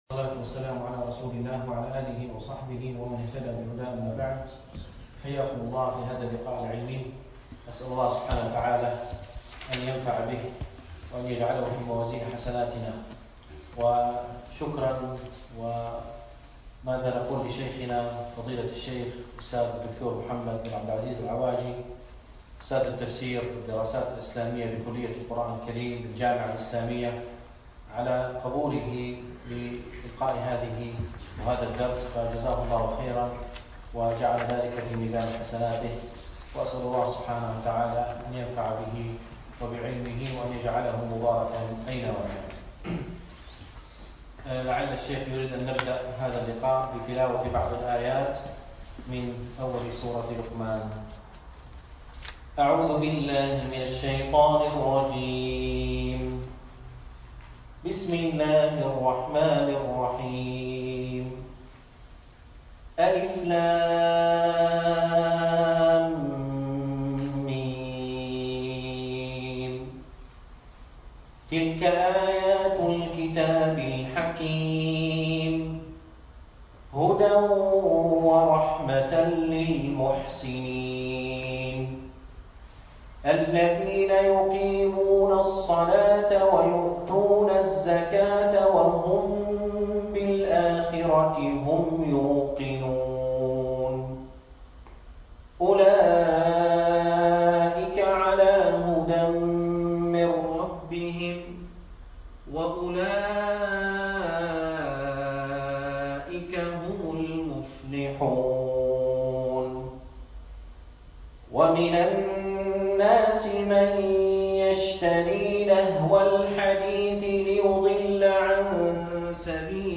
لقاء مشروع تعظيم الوحيين بالمدينة النبوية